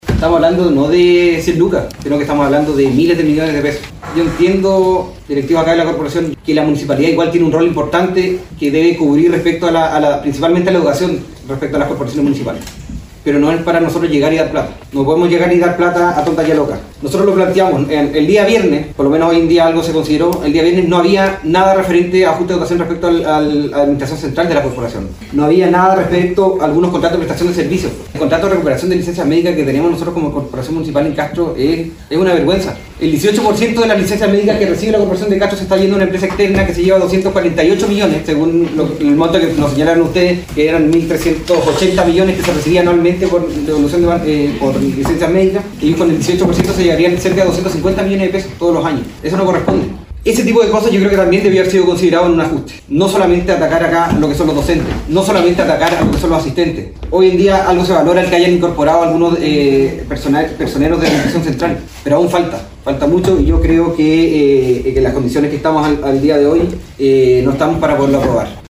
Misma posición manifestó el Concejal Ignacio Álvarez quien también votó en contra de la aprobación de este plan: